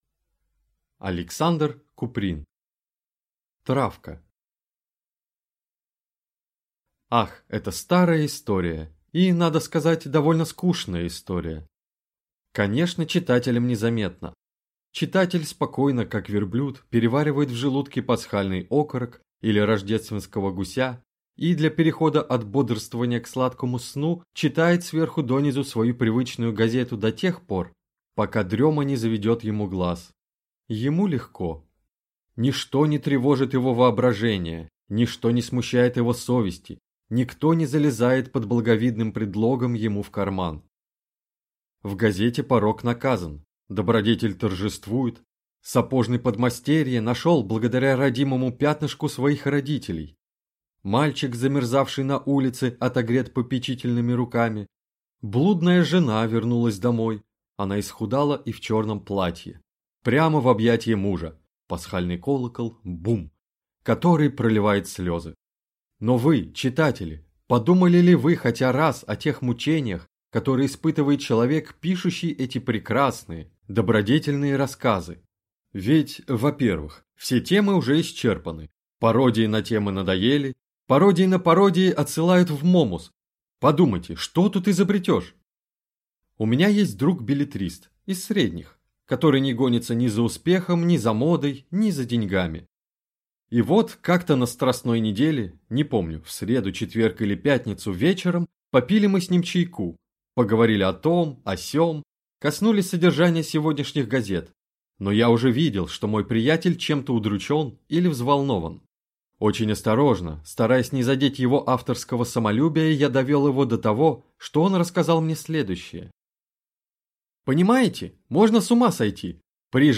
Аудиокнига Травка | Библиотека аудиокниг